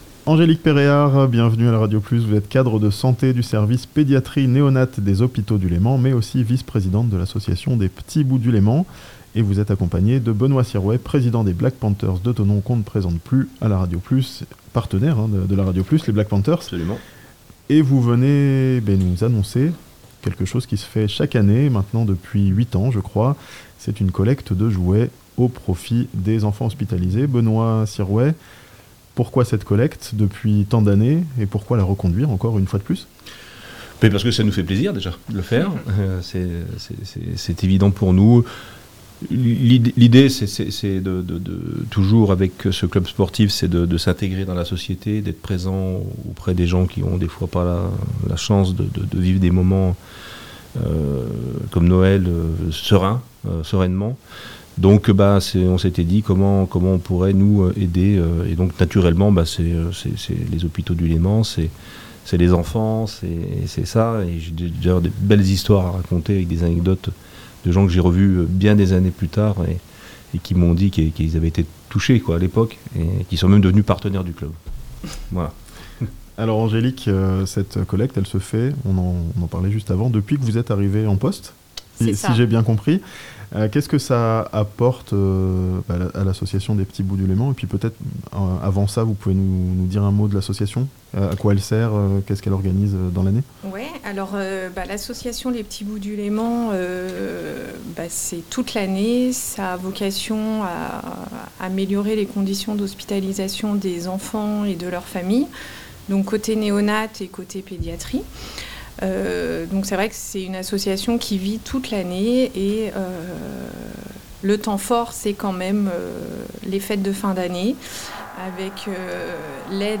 A Thonon, les footballeurs américains collectent des jouets pour les enfants hospitalisés (interview)